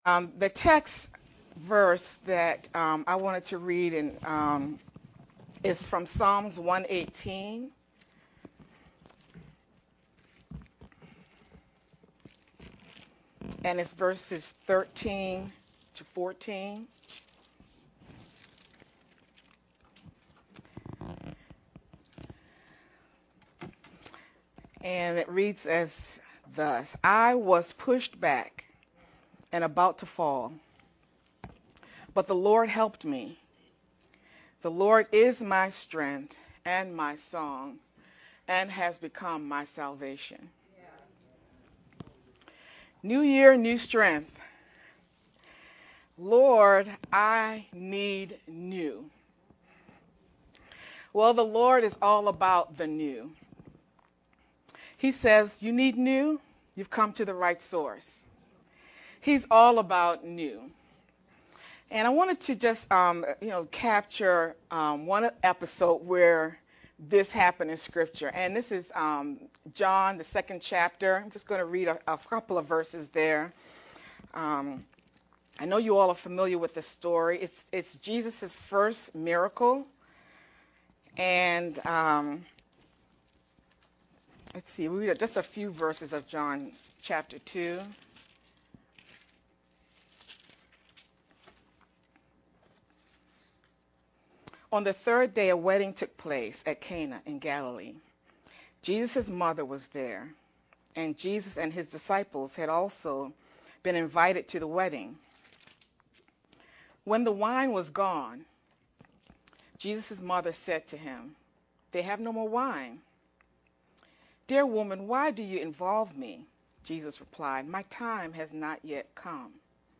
VBCC-Sermon-edited-1-29-sermon-only-Mp3-CD.mp3